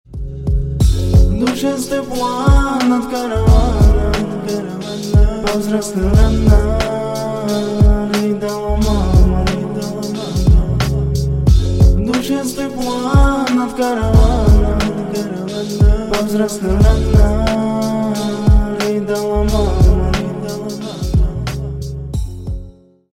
Спокойные И Тихие
Рэп Хип-Хоп